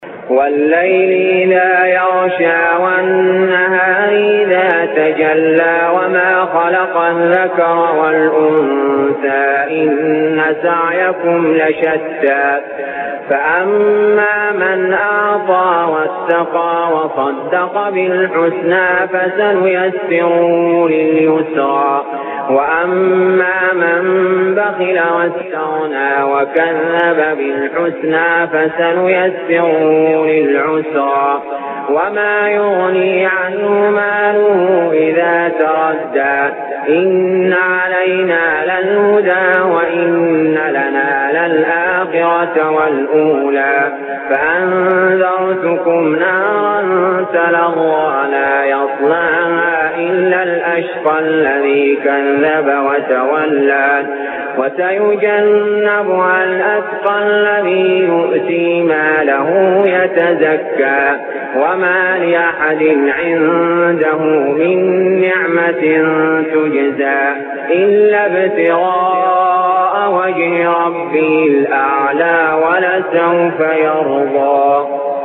المكان: المسجد الحرام الشيخ: علي جابر رحمه الله علي جابر رحمه الله الليل